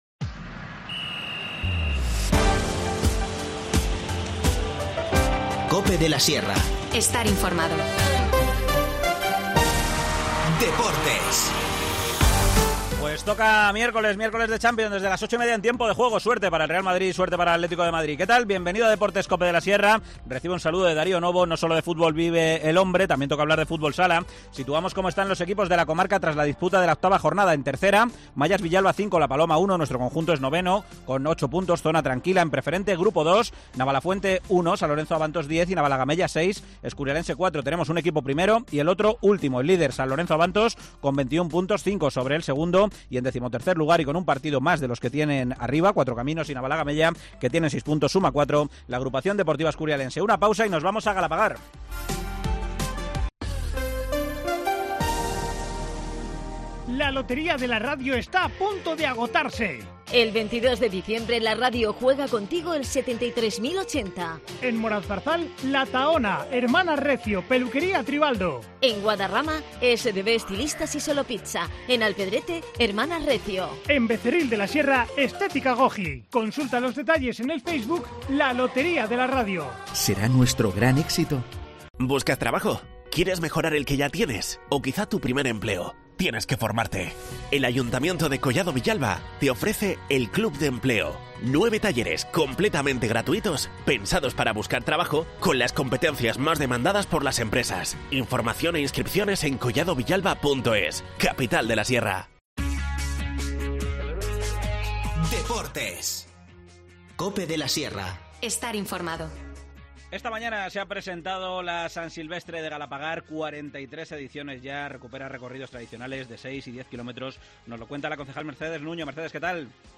Esta mañana se ha presentado la edición número 43 de la San Silvestre de Galapagar. Nos lo cuenta la concejal de Deportes, Mercedes Nuño.